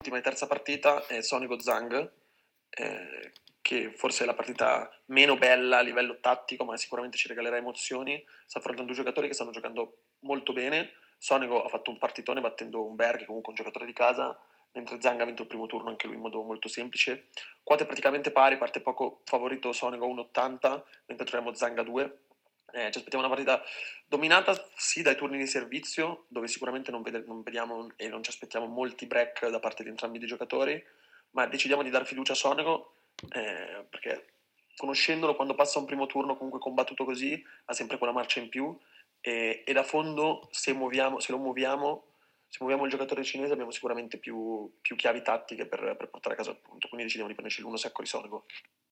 Ecco l’audio analisi